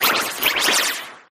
greedent_ambient.ogg